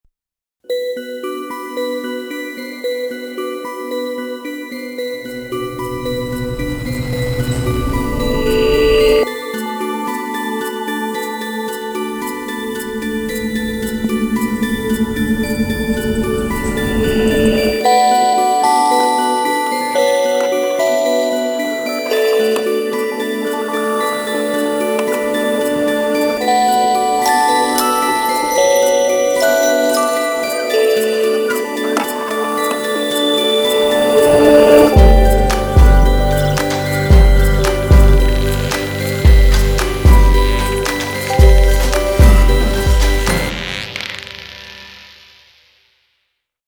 富有可玩性的创意音源，采样自反常规金属声源，可用于营造缥缈质感或制作附调打击乐。
空灵飘渺的神秘声纹
超现实机械装置
当激活时，Motion 运动功能将自动调整 Forge 或 FX 旋钮，制造出随时间而动态变化的声音效果。